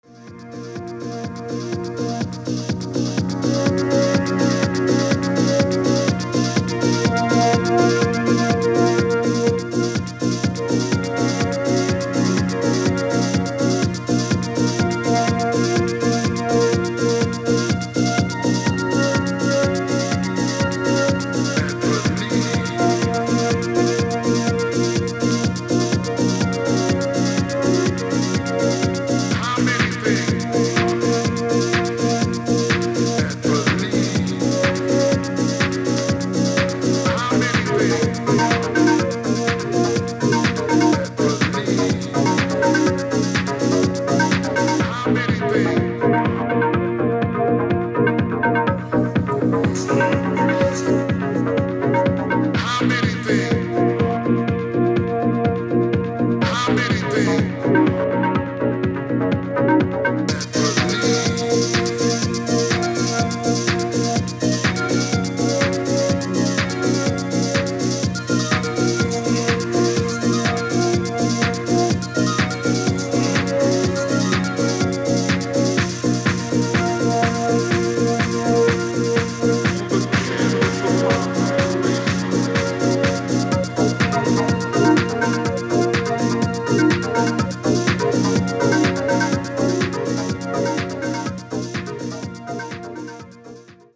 進化したACID,DEEP HOUSE感たまんないですね！！！